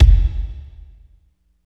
29.07 KICK.wav